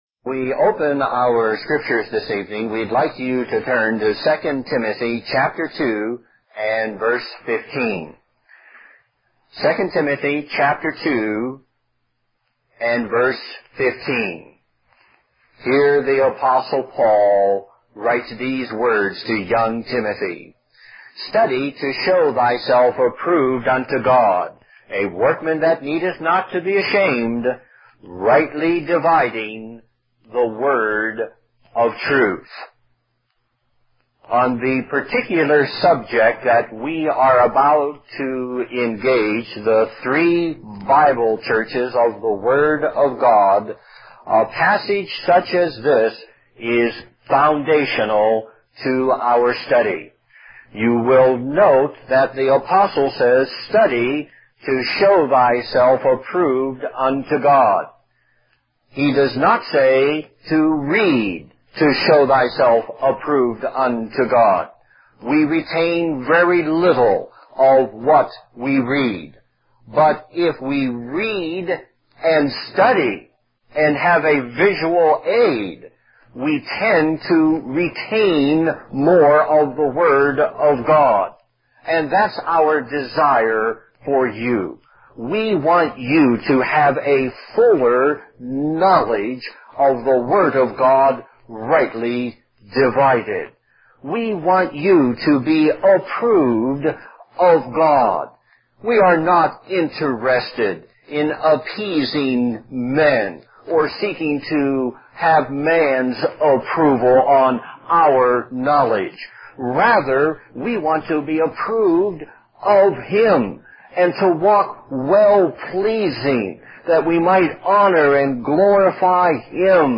Lesson 3: The Church in the Wilderness